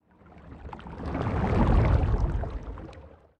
Sfx_creature_glowwhale_swim_fast_01.ogg